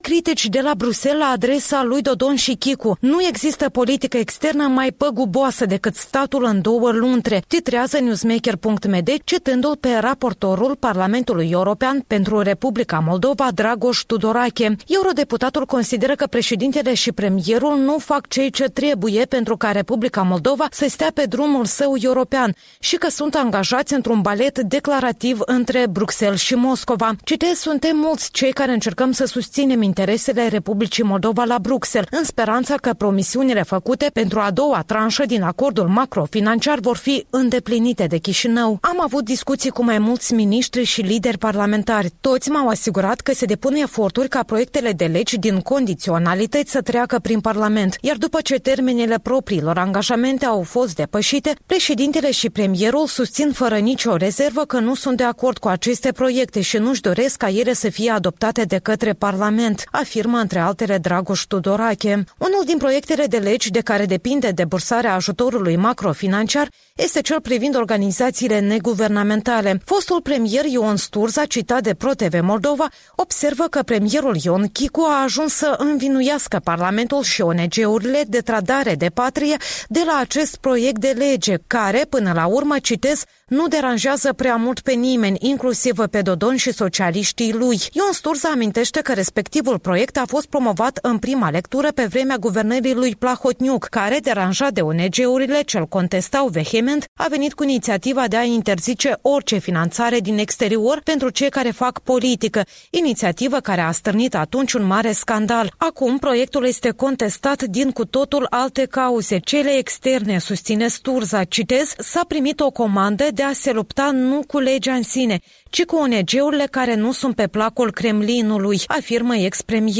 Revista presei matinale la radio Europa Liberă.